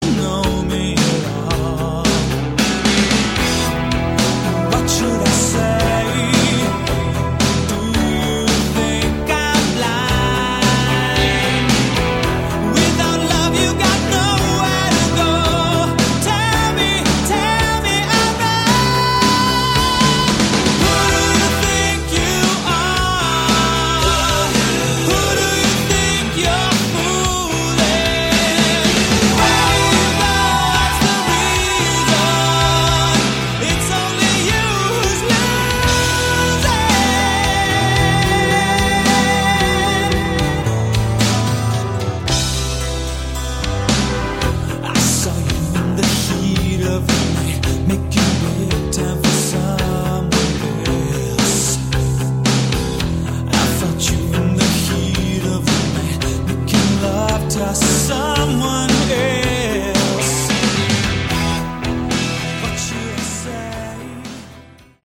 Category: AOR / Melodic Rock
Vocals
Guitars
Bass
Keyboards
Drums